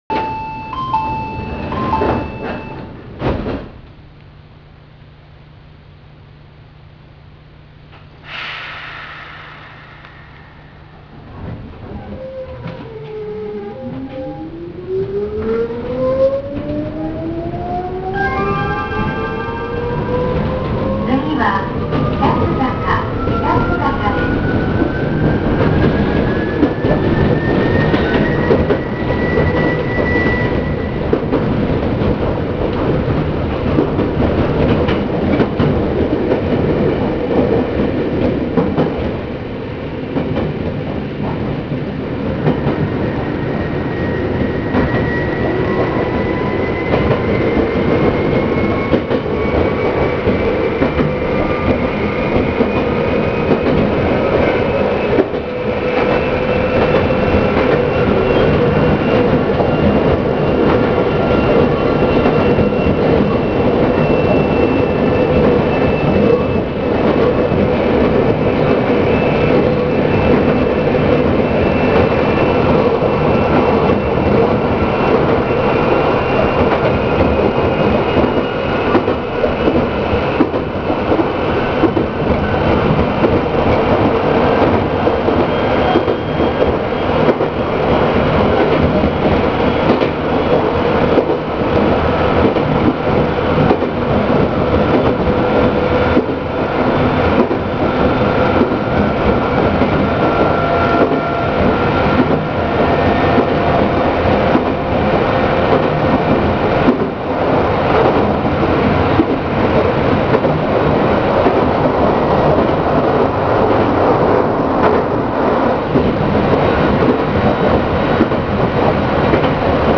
・8500系走行音
【長野線】小布施→北須坂（3分23秒：1.07MB）
当たり前ですが、東急8500系そのまんまの走行音です。新たに自動放送とドアチャイムが設置されています。
ドアチャイムは伊豆急行に譲渡された8000系と同様の物を使用している模様。